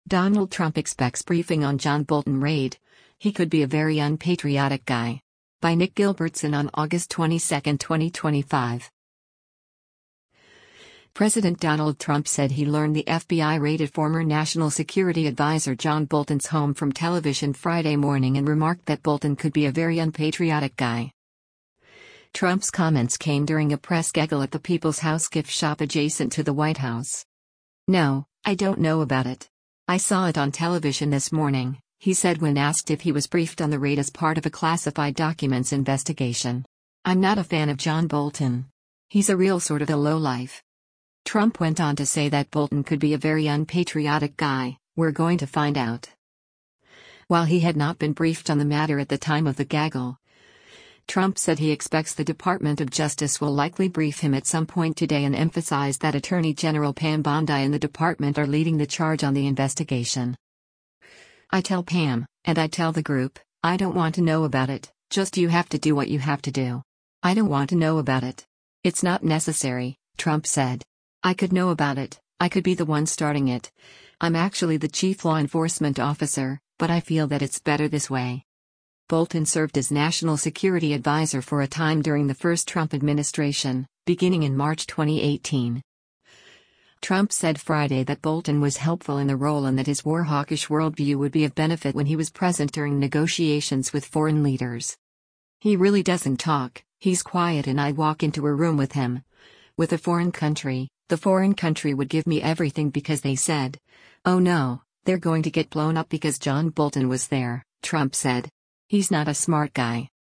Trump’s comments came during a press gaggle at the People’s House gift shop adjacent to the White House.